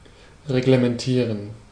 Ääntäminen
IPA: /ʁeɡləmɛnˈtiːʁən/ IPA: [ʁeɡləmɛnˈtʰiːɐ̯n]